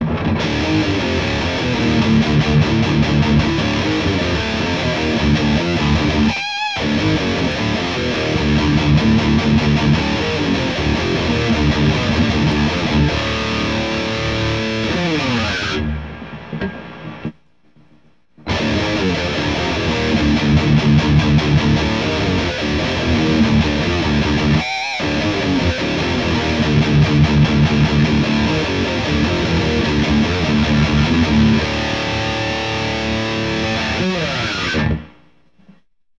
BOSS NS-2 Noise Suppressorを使ったサンプルサウンドです。
歪み系エフェクターとして、MXR ZW-44 WYLDE OVERDRIVEでちょいブーストしてます。
NS-2 OFF NS-2 ONの順番になってます。
アンプはPEAVEY EXPRESS112です。